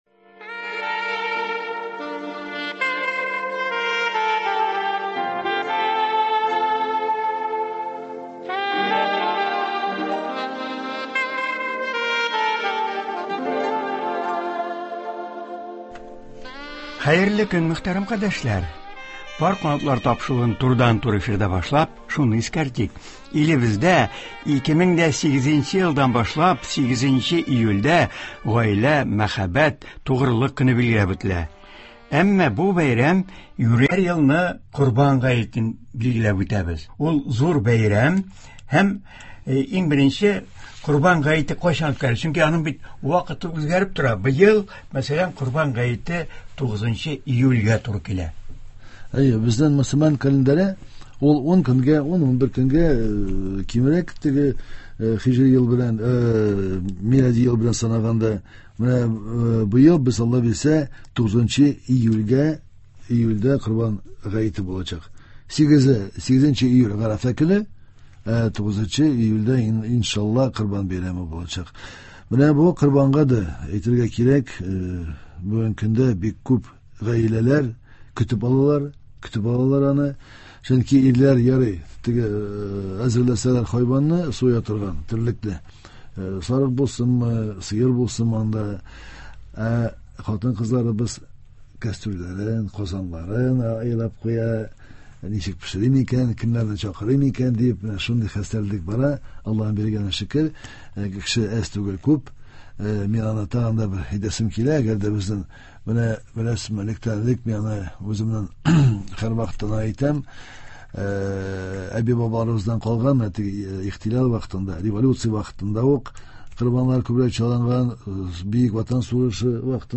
Болар хакында һәм Корбан бәйрәме көнне күрелергә тиешле чаралар турында ул турыдан-туры эфирда тәфсилләп сөйләячәк һәм тыңлаучыларны кызыксындырган сорауларга җавап бирәчәк.